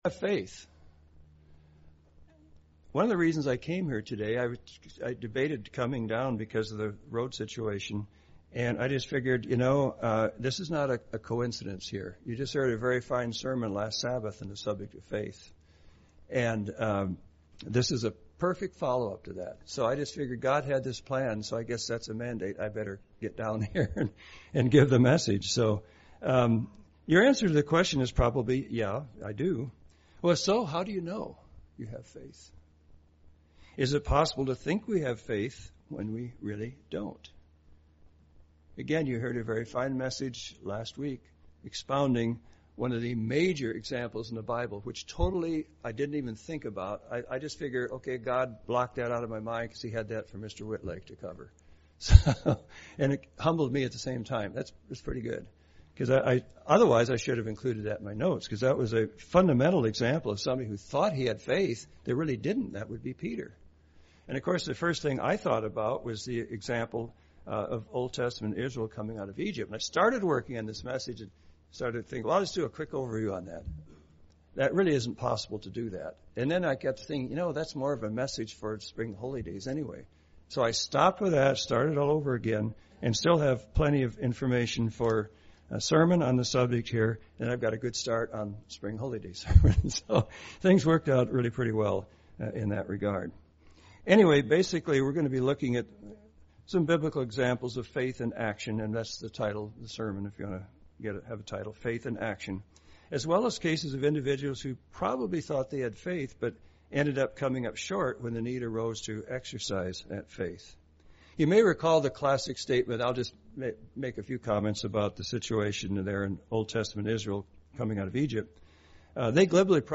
UCG Sermon faith and works Studying the bible?